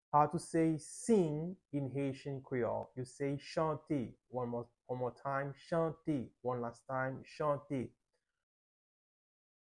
Listen to and watch “chante” audio pronunciation in Haitian Creole by a native Haitian  in the video below:
18.How-to-say-Sing-in-Haitian-Creole-–-chante-with-pronunciation-.mp3